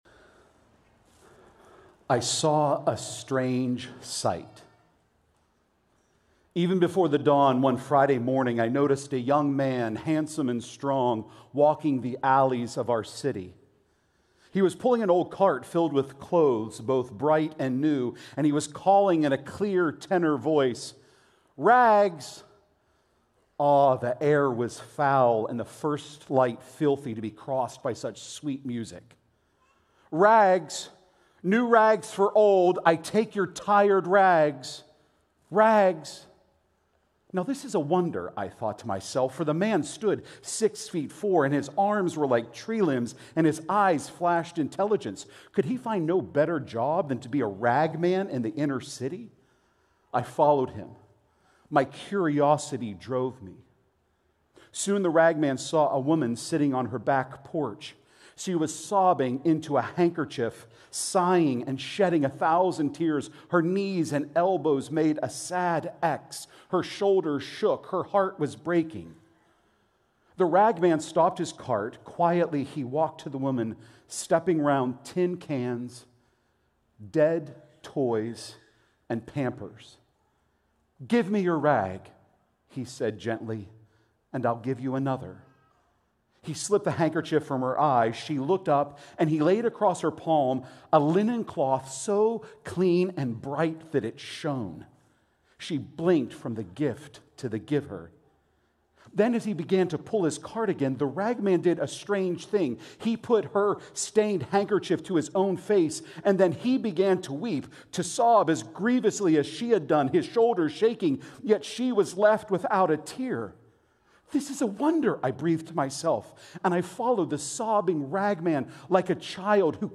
Prev Previous Sermon Next Sermon Next Title The Great Exchange